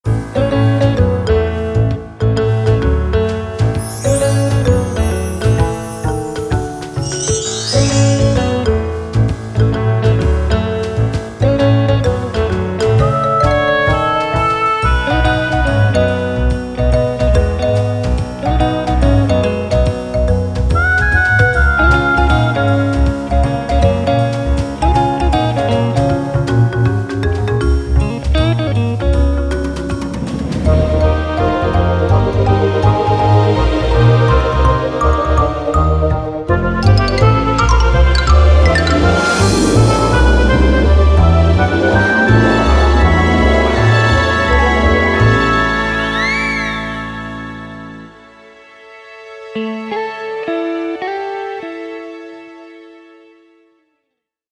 Kids
Band-Orch